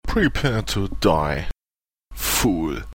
Englische Sprecher (m)